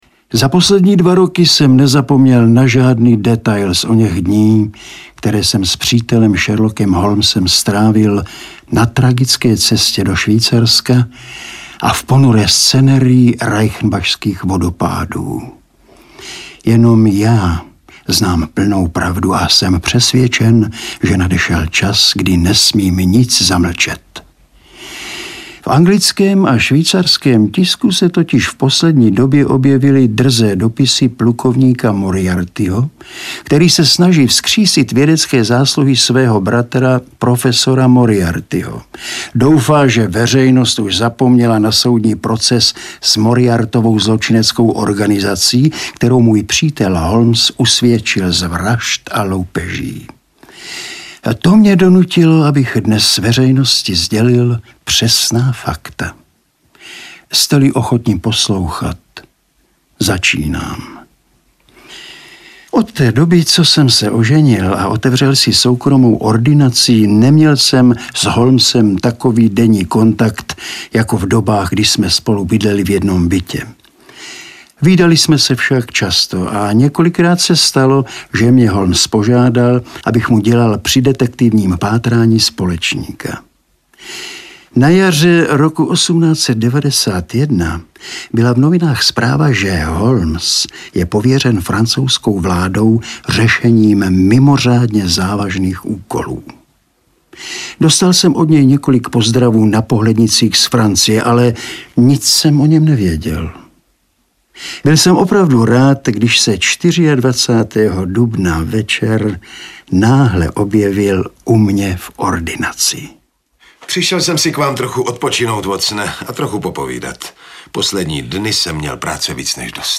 Sherlock Holmes - Vzpomínka na prázdný dům / Dr.Watson vzpomíná - Arthur Conan Doyle - Audiokniha
• Čte: Alois Švehlík, Ilja Prachař